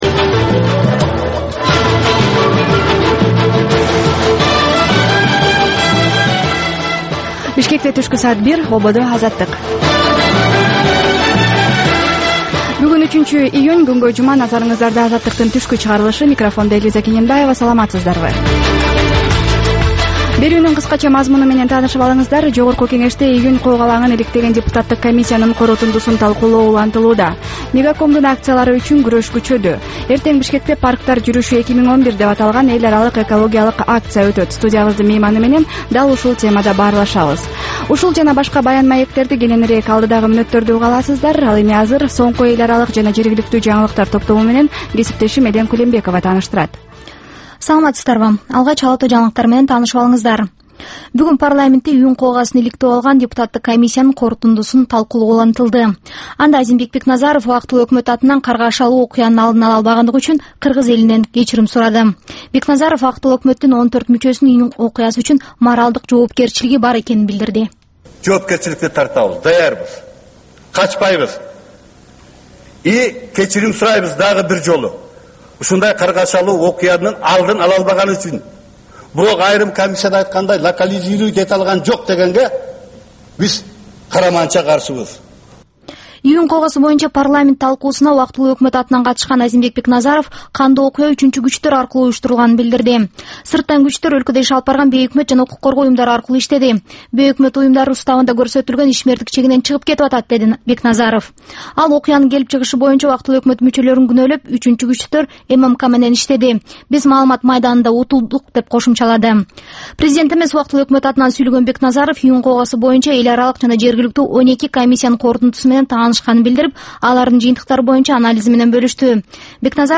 Түшкү саат 1деги кабарлар